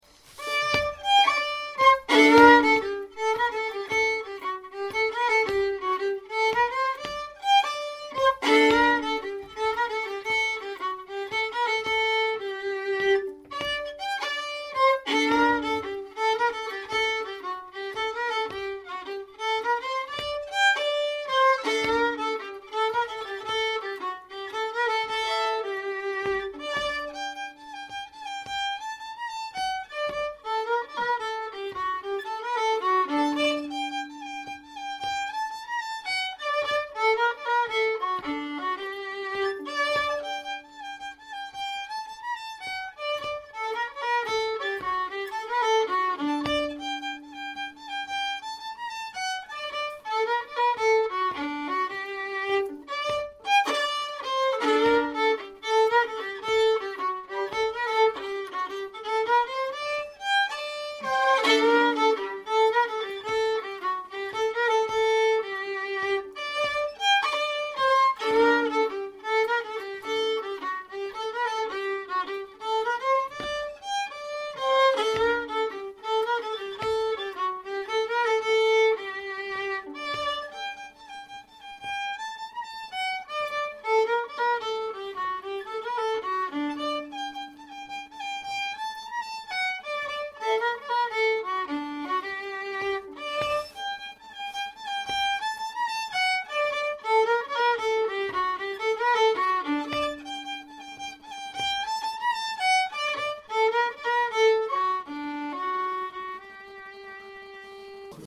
Vis- och fiolkurs
Hammerdalspolska - trad